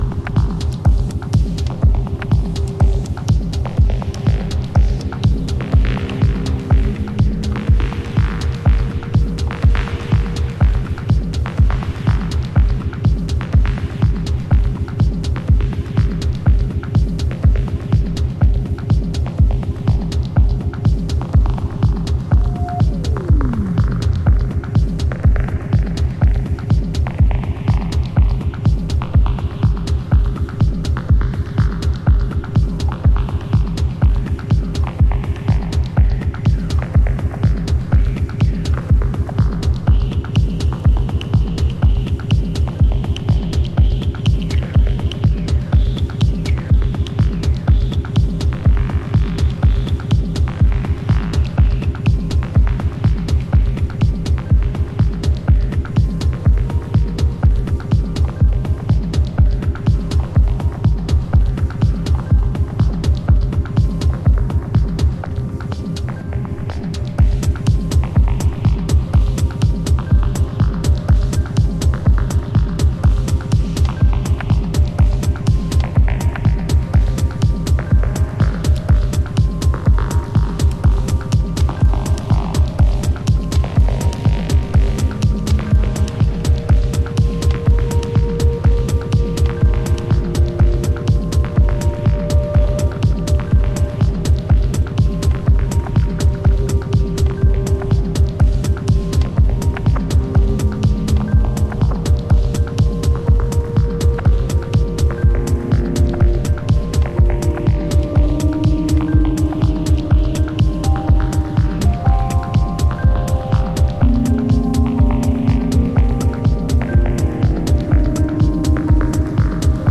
頭から尻尾まで溶けてる、白昼夢マイクロ・ミニマル